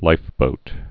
(līfbōt)